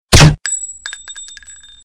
Vystrel iz pistoleta s glushitelem-sound-HIingtone
vystrel-iz-pistoleta-s-glushitelem_25042.mp3